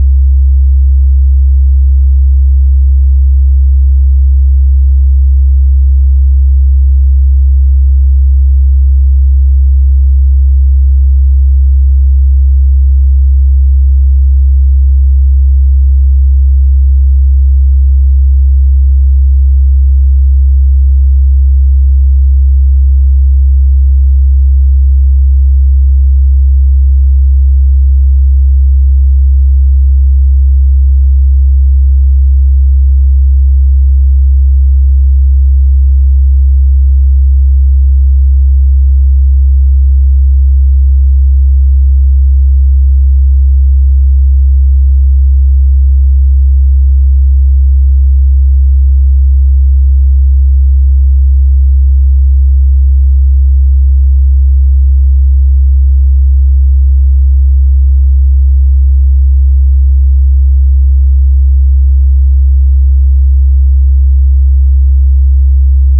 [D66] ∿ 66s 67Hz ∿
/*∿* 66s 67Hz *∿ */ ≅ D♭ (Des) /** / -------------- next part -------------- An HTML attachment was scrubbed...